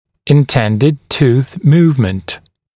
[ɪn’tendɪd tuːθ ‘muːvmənt][ин’тэндид ту:с ‘му:вмэнт]задуманное перемещение зубов